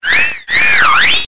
Audio / SE / Cries / GOSSIFLEUR.ogg
GOSSIFLEUR.ogg